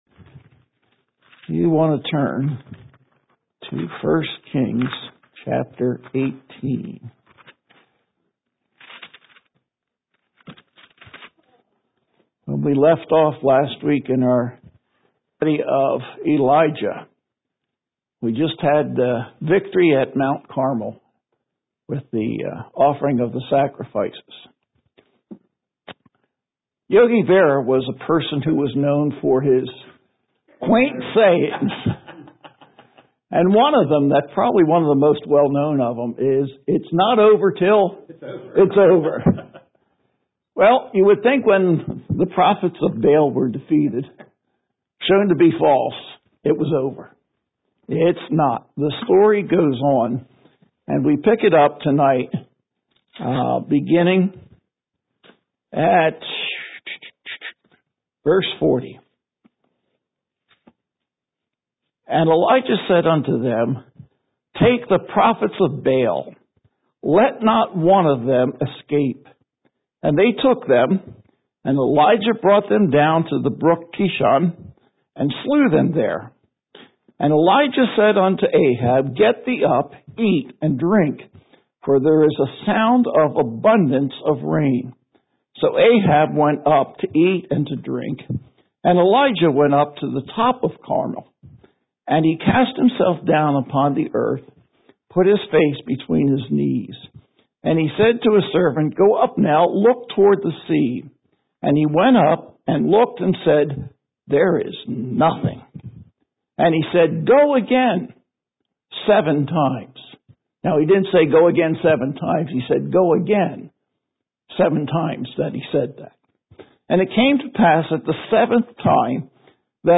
Worship Messages